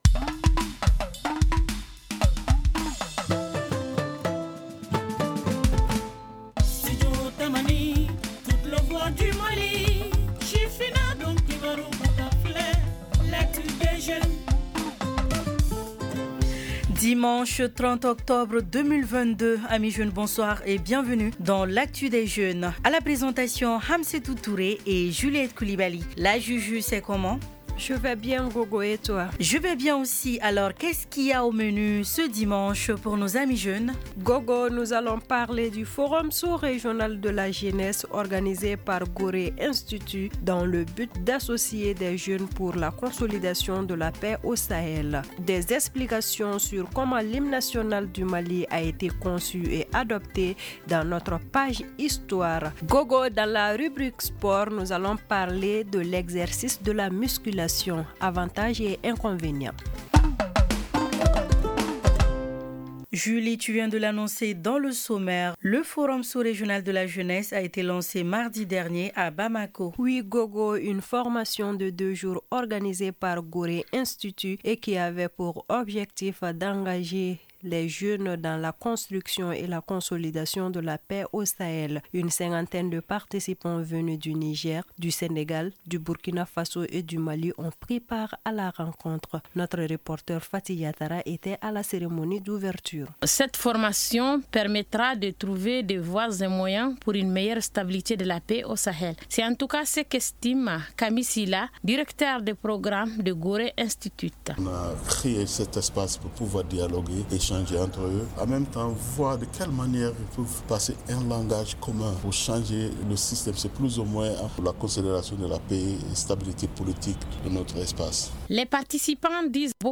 Rencontre des jeunes cette semaine au Mali pour la consolidation de la paix au Sahel. Reportage de Studio Tamani à suivre dans cette édition.